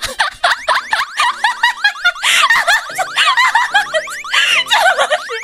Laugh.wav